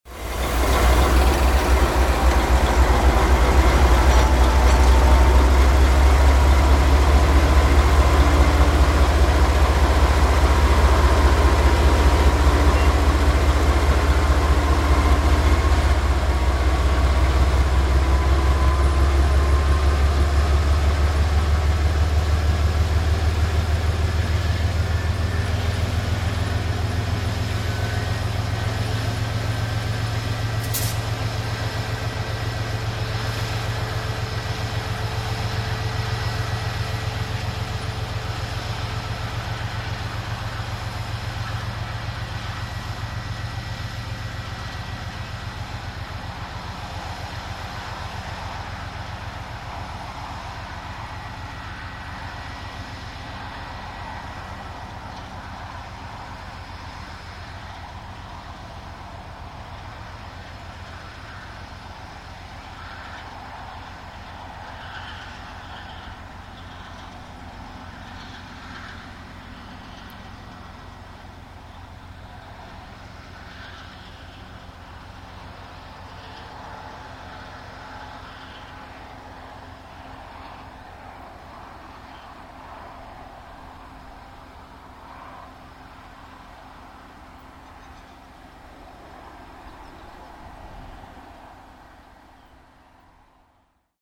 More Diesel Recordings
Have a listen to 2840 reversing her train back up the bank towards The Leap.